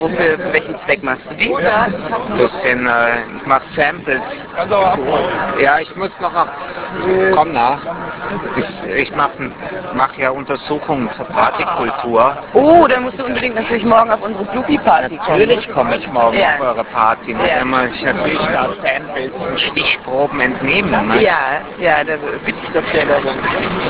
Some special audio-samples from the 1995 Frankfurt Club-Life
Sample bei Arosa 2000, 19.5.1995, Stichproben